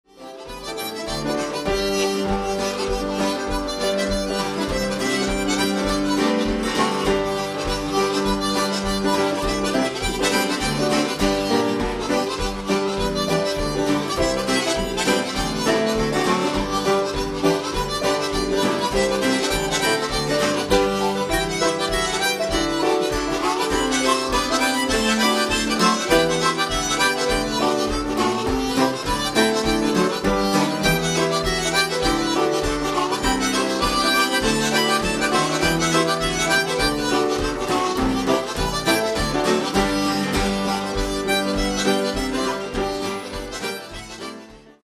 Great lively dance music here
Harmonica backed by piano, guitar and tenor guitar.